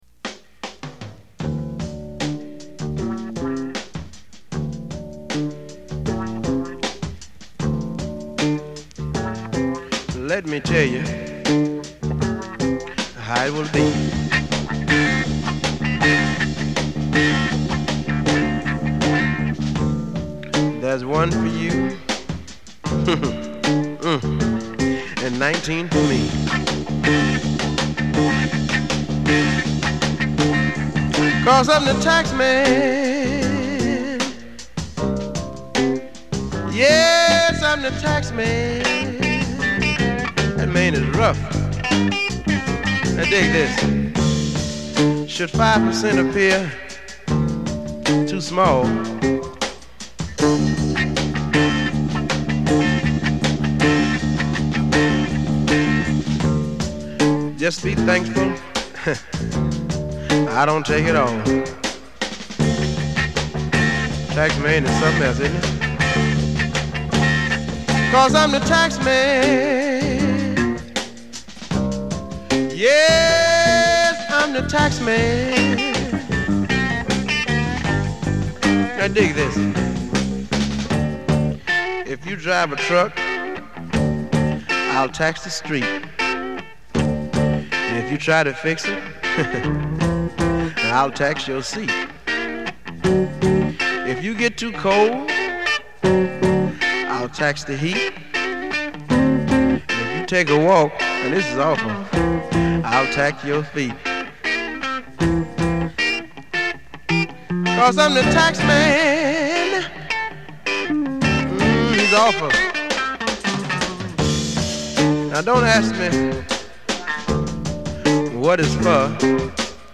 Soul Version
slow soul version